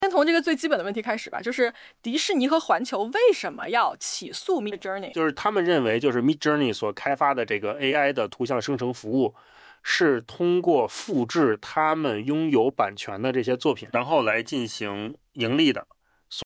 豆包交付的AI播客节目以男女双人对谈的形式进行，能对用户上传的网页、文件等进行深度改造，使其更适合听众消费。
实测多个案例后，智东西发现豆包生成的AI播客基本遵循了一个特定的模式——女主持人负责控制播客的节奏、提问等，男主持人负责主要内容的讲述。
具体的收听体验层面，可以听到AI主播们会通过调整语速等方式来强调重点信息。
也会使用一些语气词和包含情感的评价，这加强了播客的“真人感”。